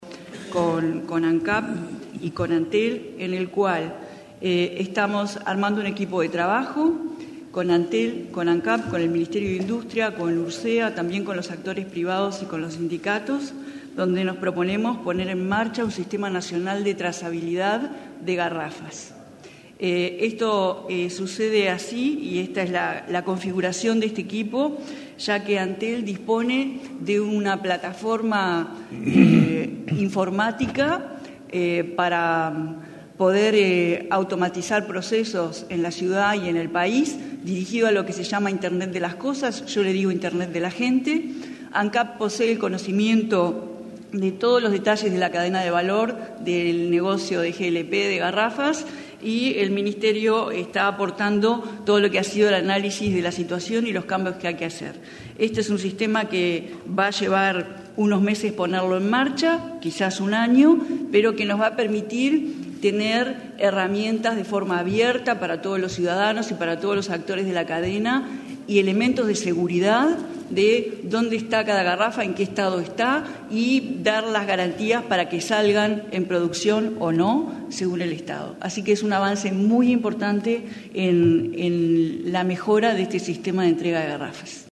El objetivo es automatizar procesos y conocer dónde se encuentra cada garrafa y cuál es su estado. La ministra Carolina Cosse informó, en el Consejo de Ministros abierto, que este programa brindará mayores garantías para la utilización de los envases de supergás.